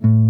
JAZZ SOLO 2-.wav